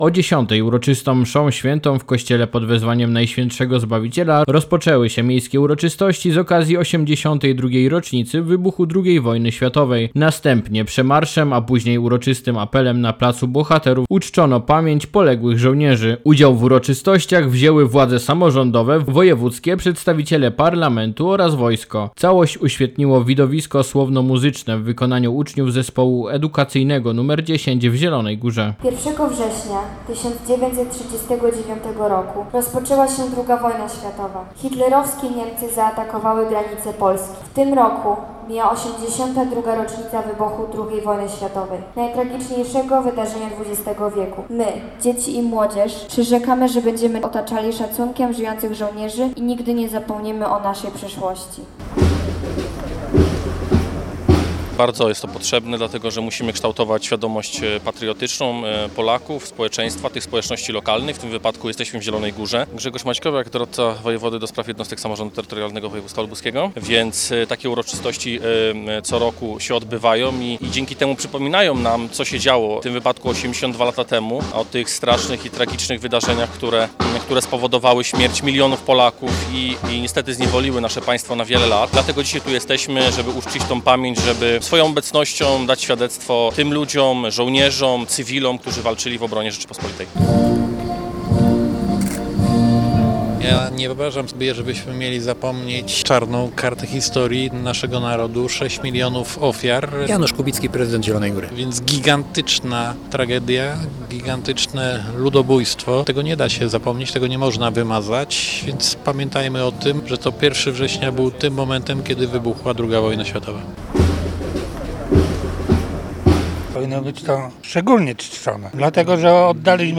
Dziś na placu Bohaterów w Zielonej Górze odbyły się obchody 82. rocznicy wybuchu II wojny światowej i Dnia Weterana Walk Niepodległość RP.
Całość uświetniło widowisko słowno-muzyczne w wykonaniu uczniów Zespołu Edukacyjnego Nr 10 w Zielonej Górze: